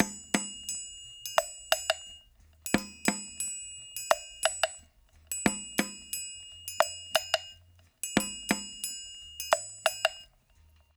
88-PERC4.wav